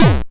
Play, download and share MG_kick_1 original sound button!!!!
kick_1.mp3